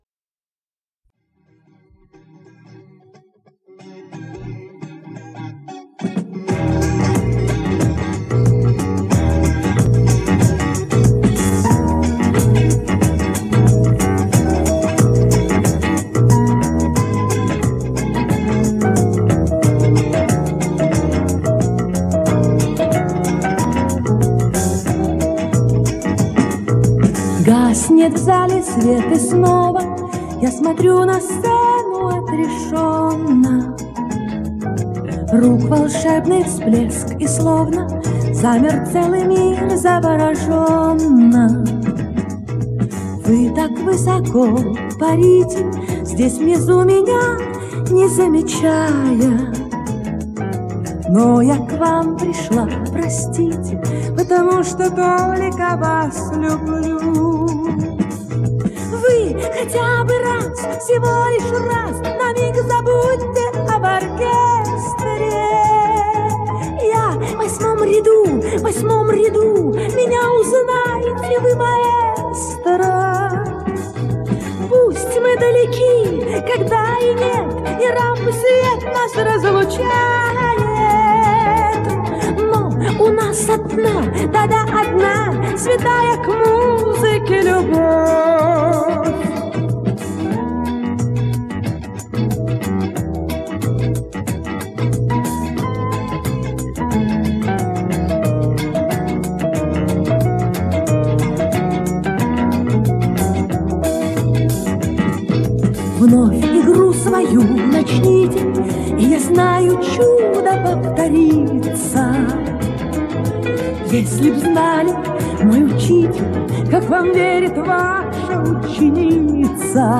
с вокализом внутри